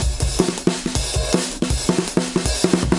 Drumloops " dnb drumloop c 2bars 160bpm
Tag: 低音 沟槽 节奏 碎拍 drumloop drumloop 断线 N 160bpm 节拍 DNB drumgroove